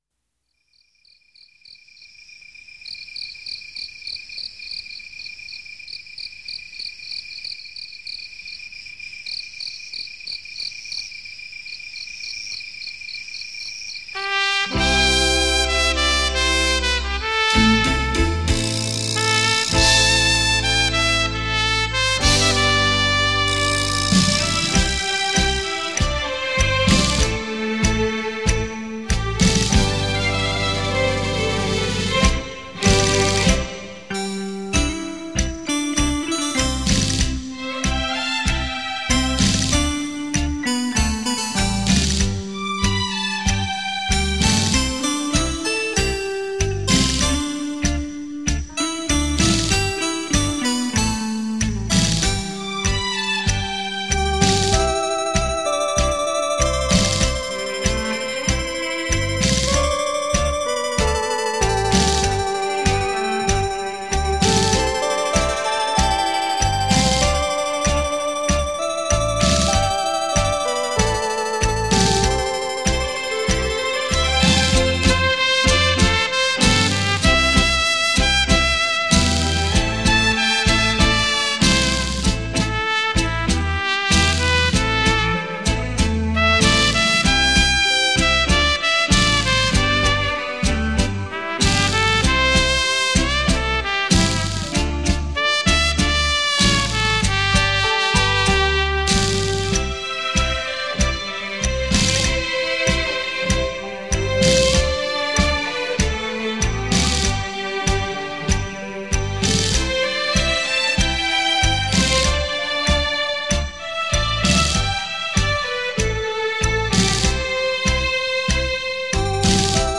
这是一张效果非凡，声场宏大的效果音乐试音碟。
超时空立体音效 百万名琴魅力大出击